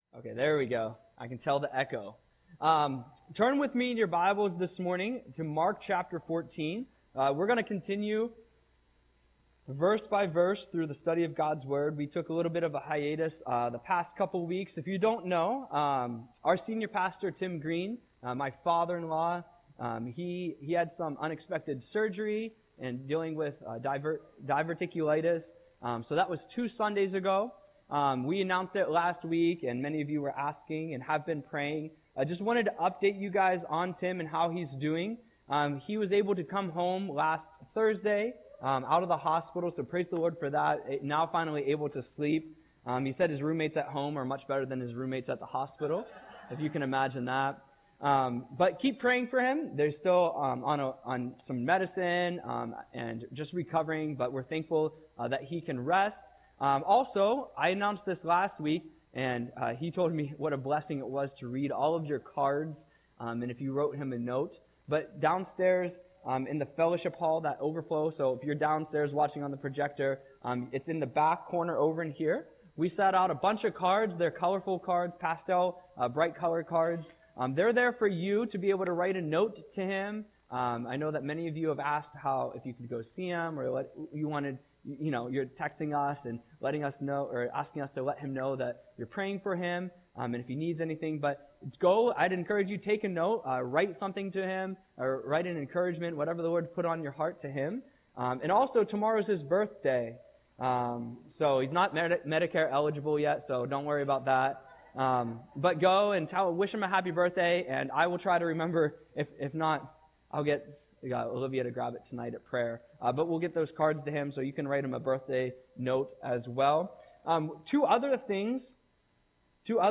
Audio Sermon - March 23, 2025